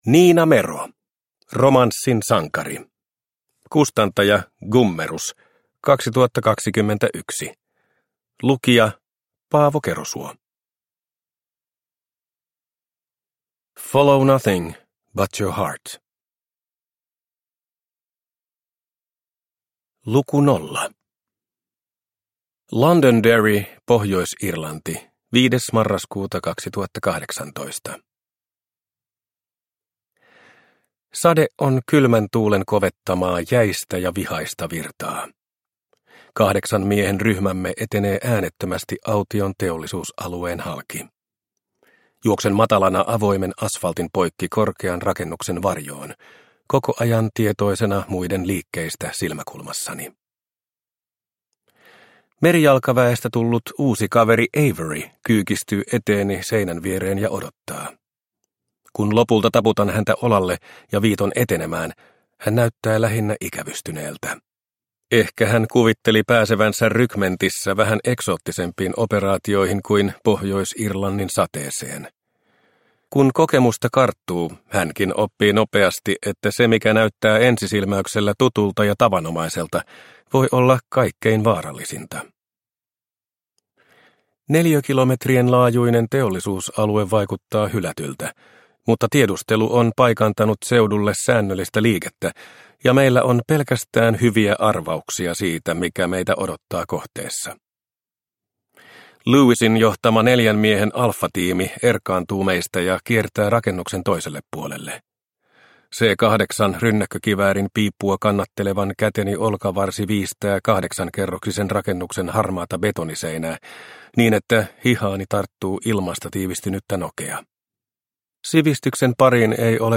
Romanssin sankari – Ljudbok – Laddas ner